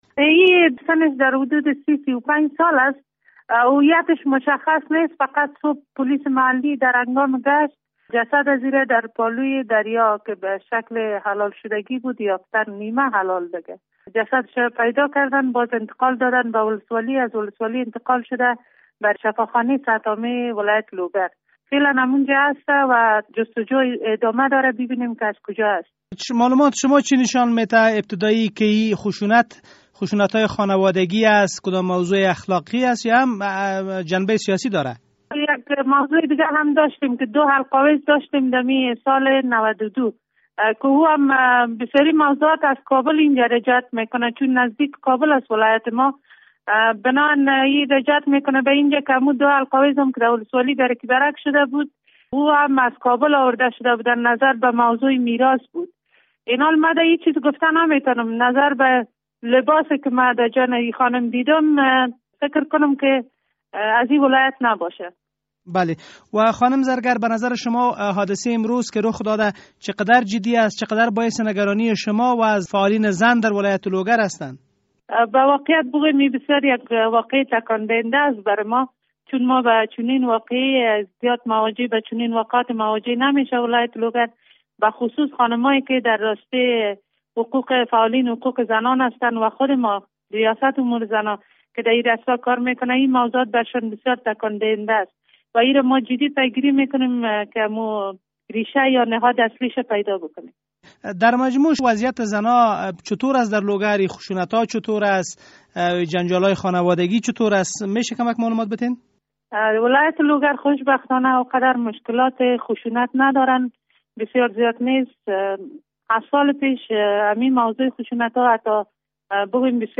مصاحبه در مورد چگونگی کشته شدن یک زن در لوگر